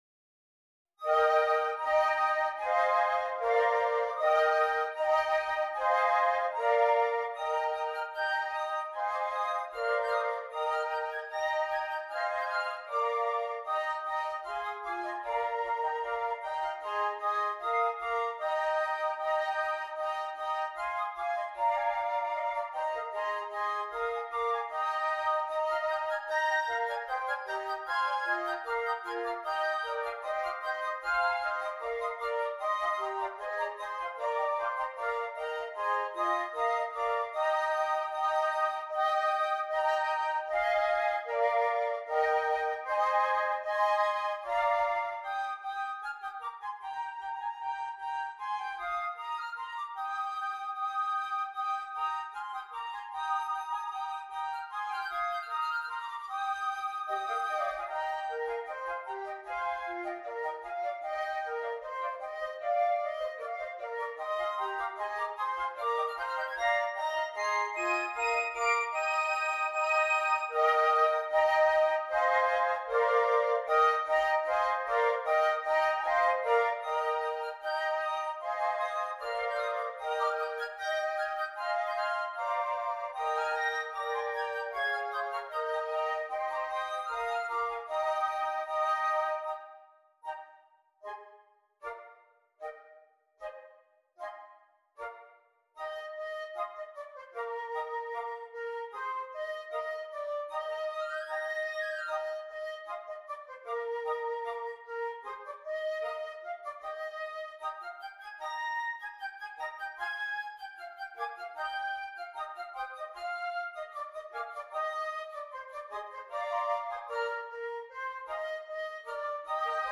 Christmas
6 Flutes
Traditional Carol